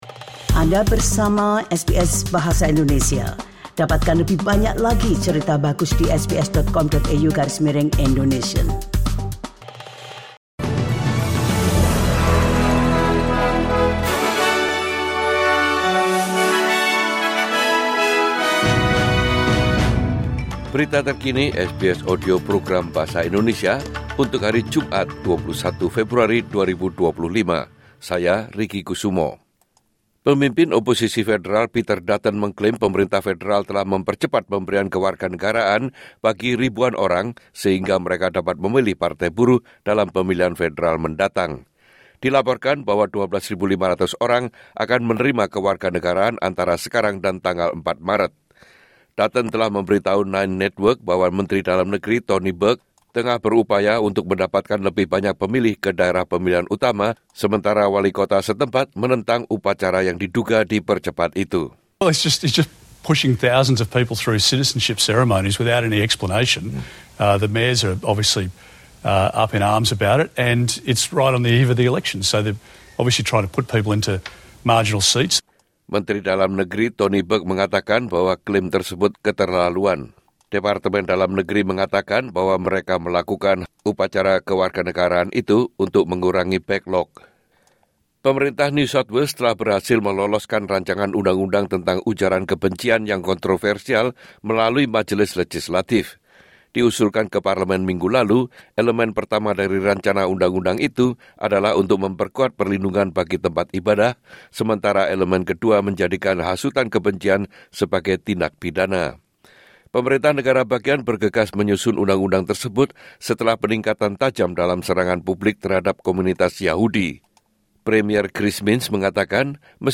Newsflash SBS Audio Program Bahasa Indonesia